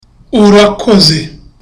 (With joy)